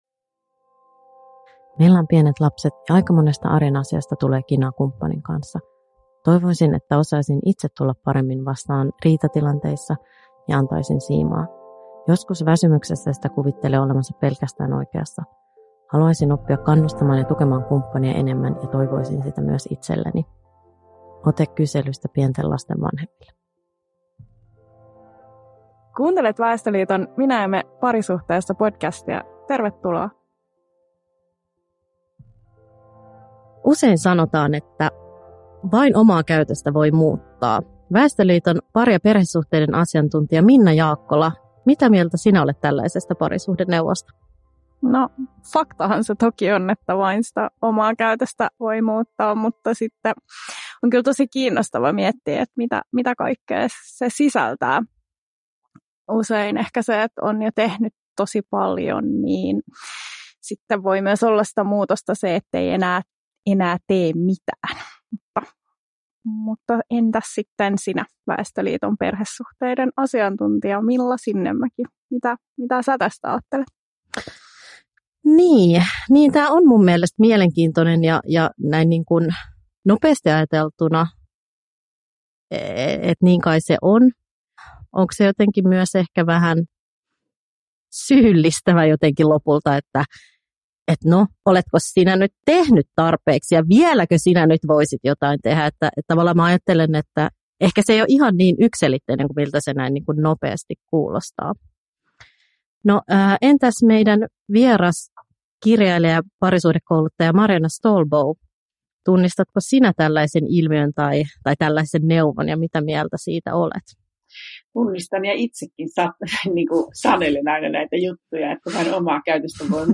Minä ja me parisuhteessa -podcastissa keskustellaan parisuhteen kysymyksistä seksuaalisuuteen, arkeen, muutokseen ja vuorovaikutukseen liittyvissä teemoissa.